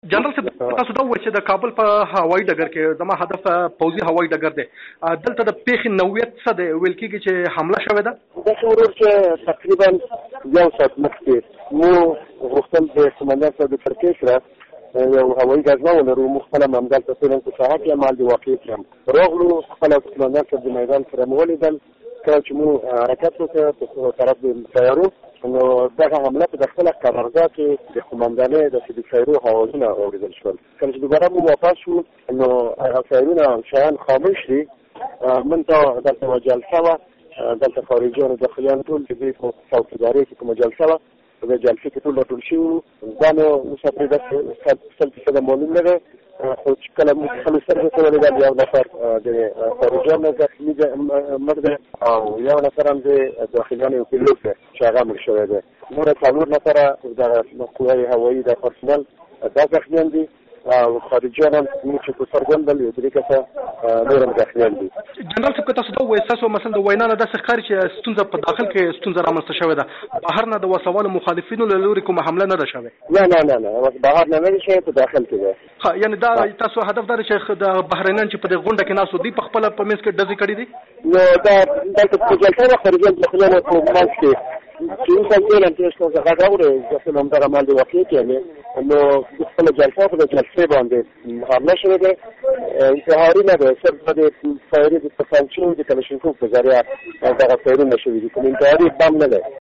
له جنرال محمد امین داؤد سره مرکه